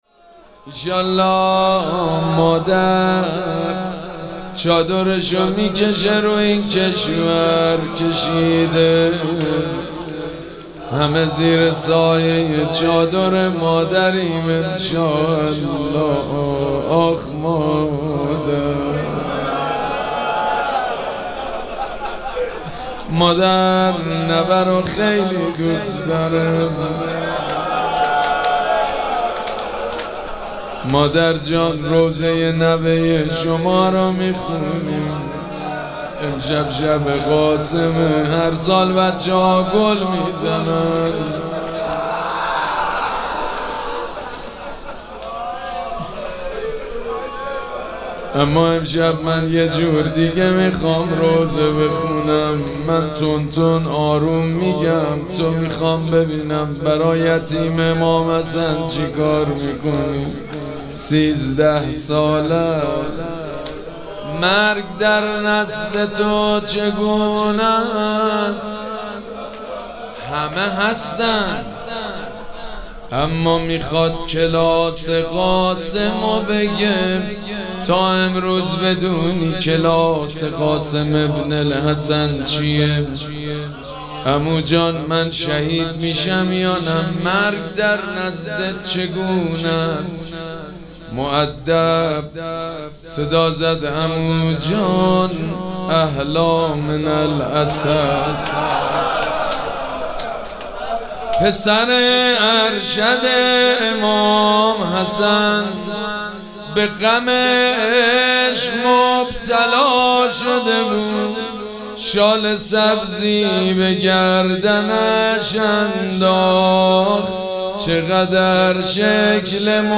مراسم عزاداری شب ششم محرم الحرام ۱۴۴۷
روضه